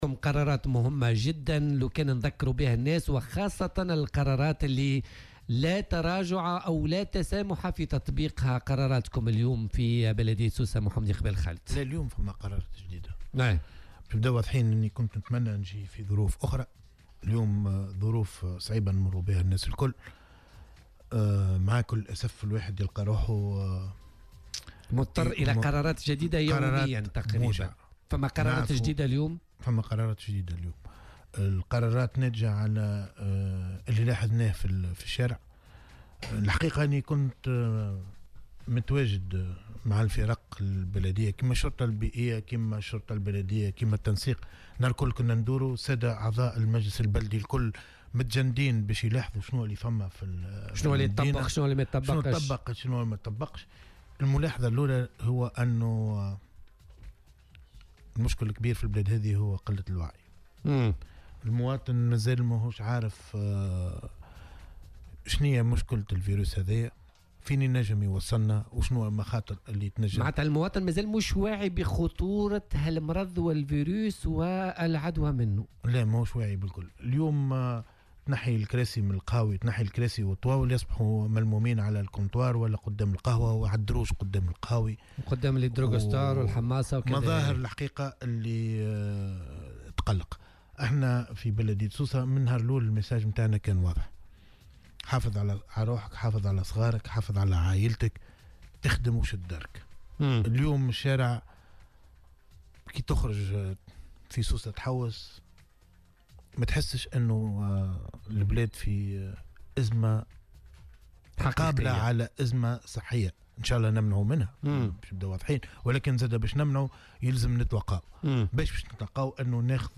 وأضاف في مداخلة له اليوم في برنامج "بوليتيكا" أنه تقرّر بداية من يوم غد إغلاق المقاهي والمطاعم ومحلات الأكلة الخفيفة طيلة اليوم، وتعليق العمل "بالدلالة" بسوق الجملة للاسماك، إضافة إلى منع قبول الحيوانات بهدف ذبحها في المسلخ البلدي بعد العاشرة ليلا و منع القصابة من الدخول لقاعات الذبح والسلخ.